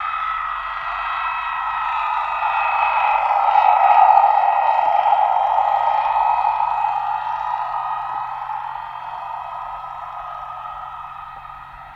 Torpedo Jet By